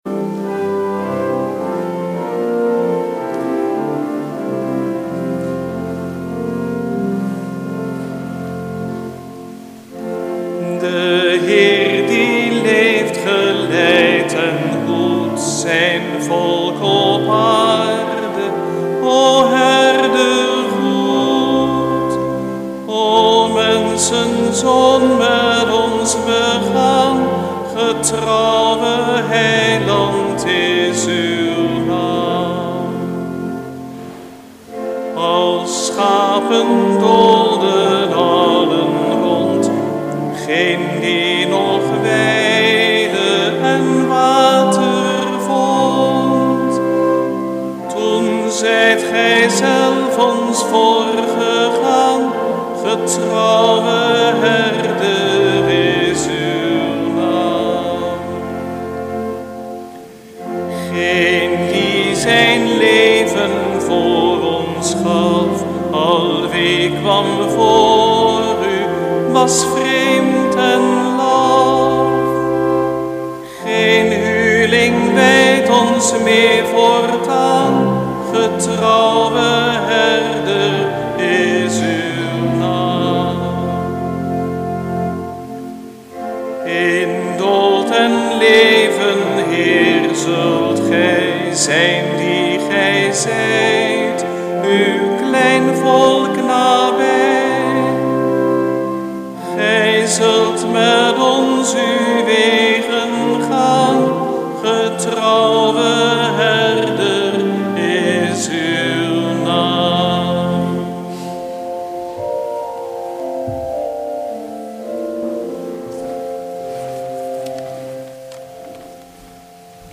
Lezingen